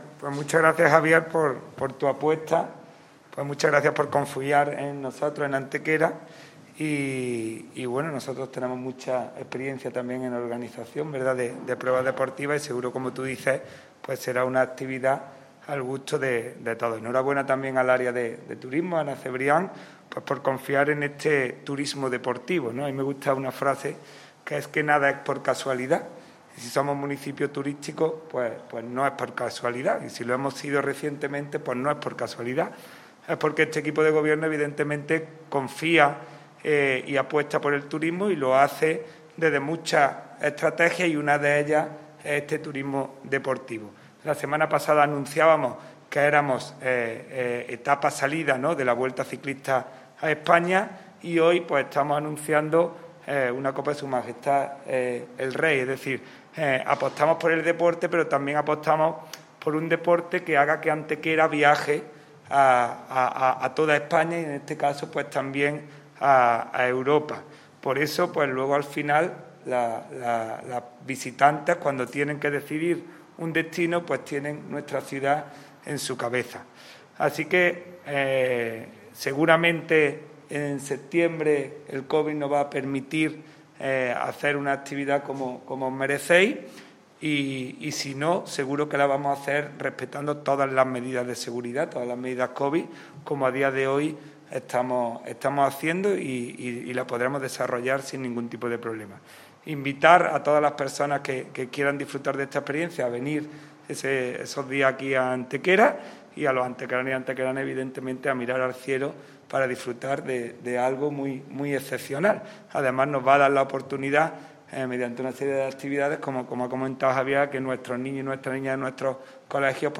La teniente de alcalde delegada de Turismo, Ana Cebrián, y el teniente de alcalde de Deportes, Juan Rosas, han confirmado hoy en rueda de prensa que Antequera acogerá durante los próximos días 29 y 30 de septiembre el inicio de la XXII Copa de S.M. el Rey de Aerostación.
Cortes de voz